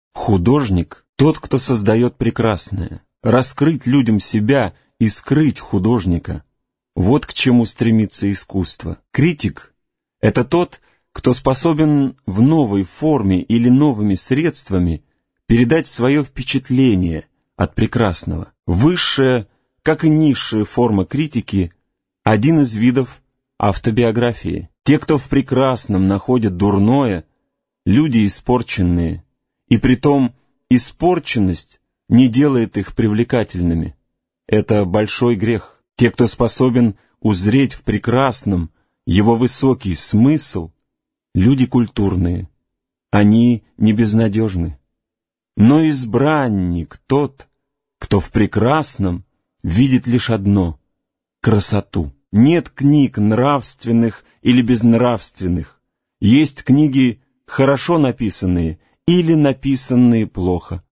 Аудиокнига Портрет Дориана Грея | Библиотека аудиокниг